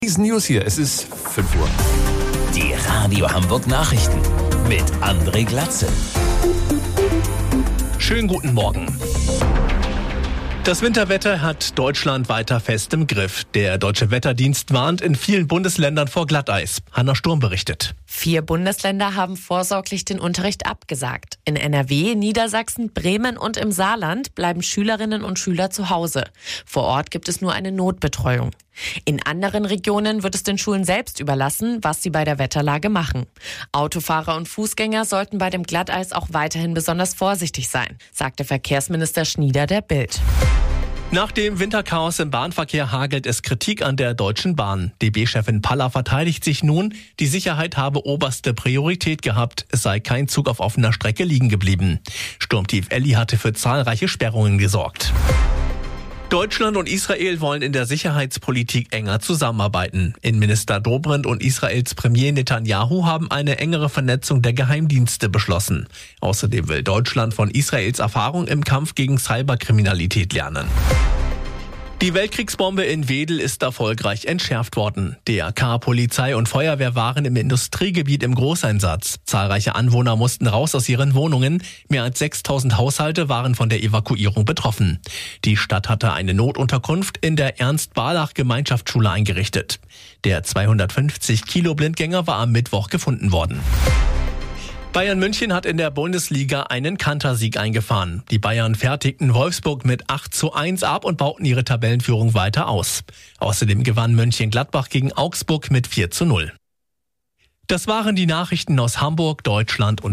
Radio Hamburg Nachrichten vom 12.01.2026 um 05 Uhr